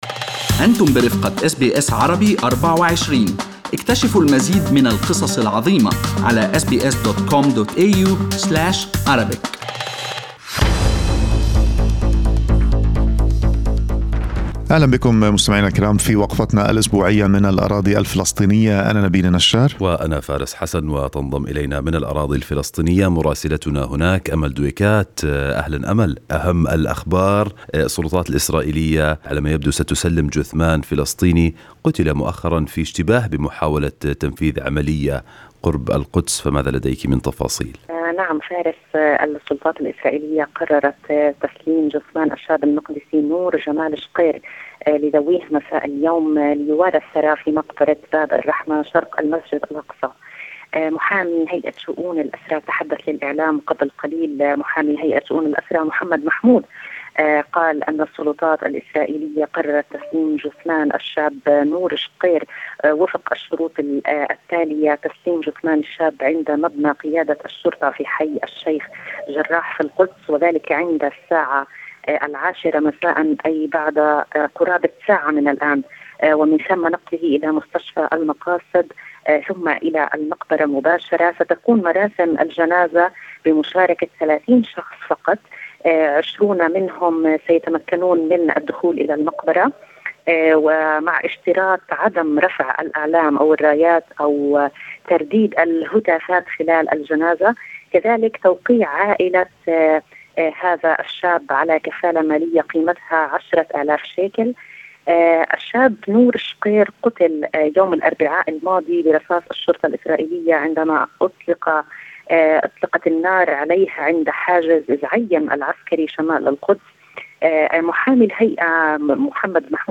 من مراسلينا: أخبار الأراضي الفلسطينية في أسبوع 30/11/2020